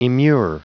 Prononciation du mot immure en anglais (fichier audio)
Prononciation du mot : immure